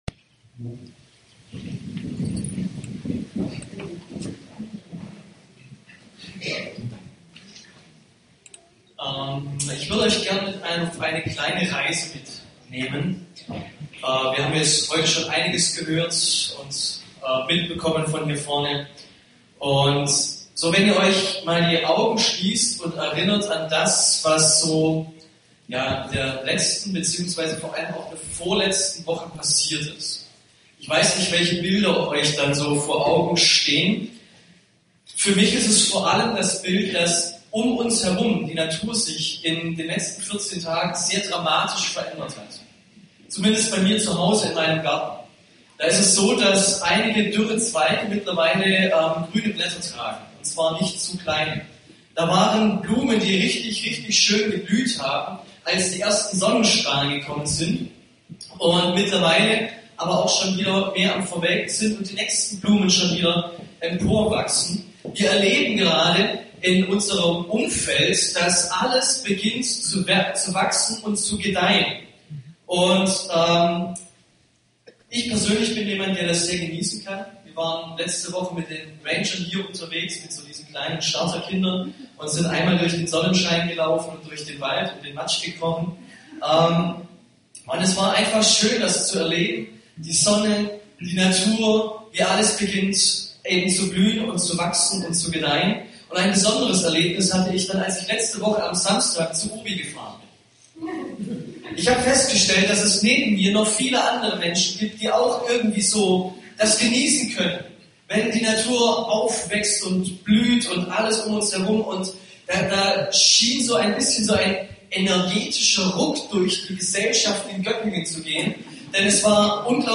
Bitte entschuldigt die Qualität der Aufnahme – aufgrund einer Umstellung der Technik hat die Aufnahme leider nicht die gewohnte Qualität!